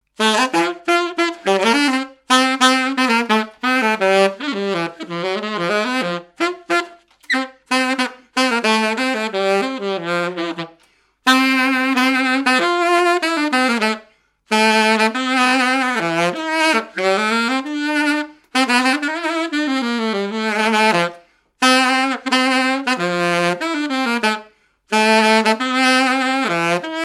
Mémoires et Patrimoines vivants - RaddO est une base de données d'archives iconographiques et sonores.
activités et répertoire d'un musicien de noces et de bals
Pièce musicale inédite